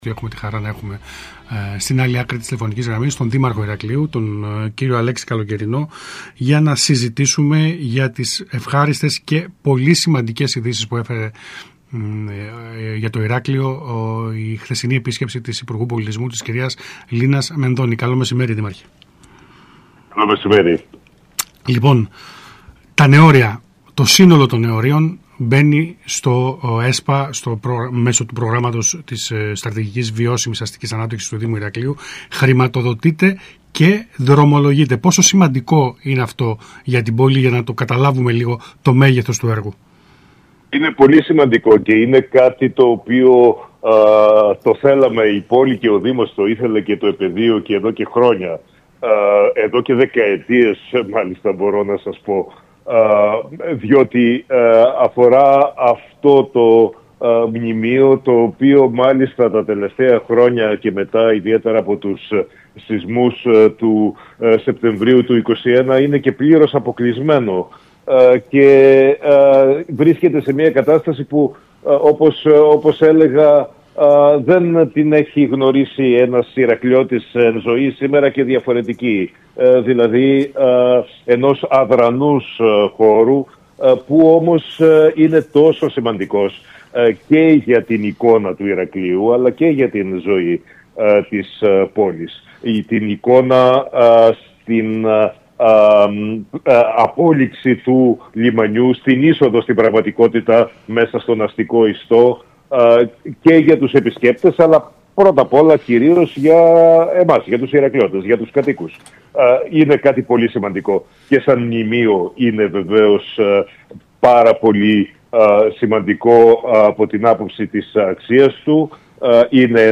Για την μεγάλη σημασία του έργου αποκατάστασης και ανάδειξης των Νεωρίων και το χρονοδιάγραμμα υλοποίησης των παρεμβάσεων που θα αλλάξουν το παραλιακό μέτωπο της πόλης μίλησε στον ΣΚΑΙ Κρήτης 92.1 ο Δήμαρχος Ηρακλείου Αλέξης Καλοκαιρινός.